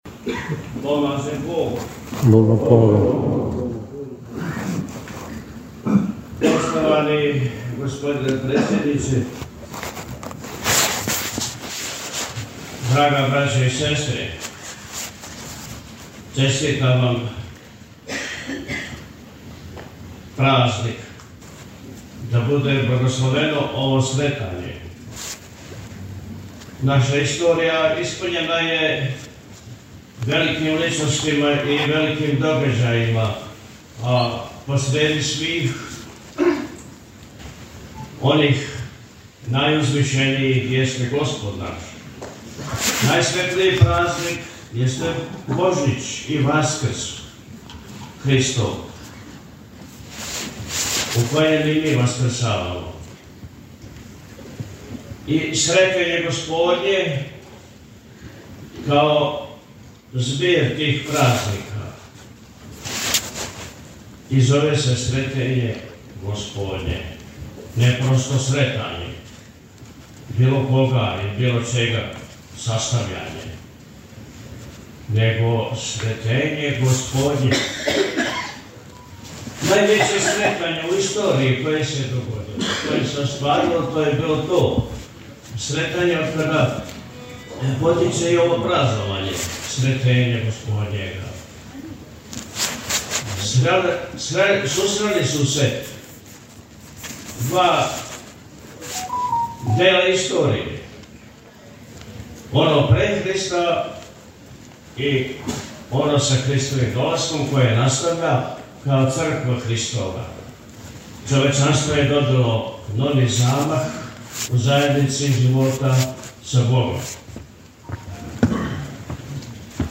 Свечана Сретењска академија у Пљевљима - Eпархија Милешевска
Поводом обележавања 221. годишњице Првог српског устанка и 190. годишњице доношења Сретењског устава Српски културни центар Патријарх Варнава из Пљеваља организовао је у петак 14. фебруара 2025. године у Центру за културу Пљевља свечану Сретењску академију.